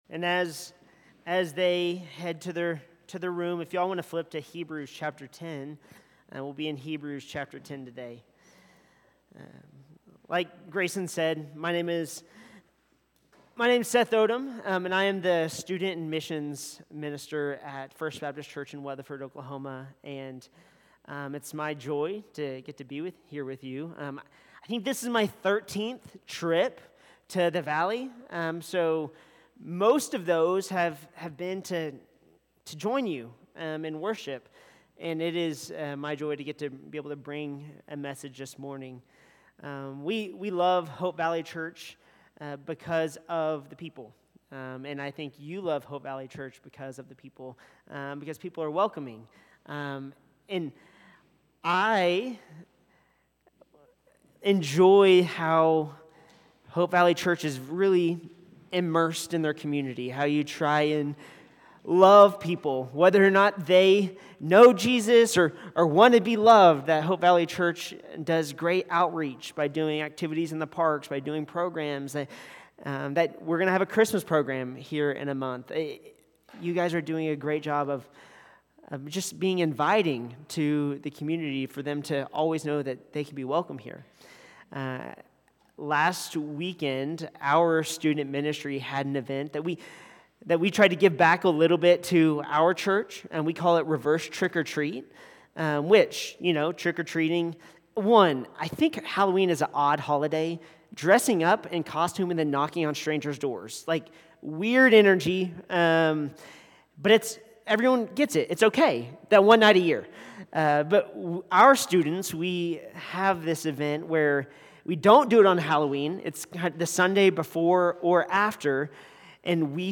From Series: "Stand-Alone Sermons"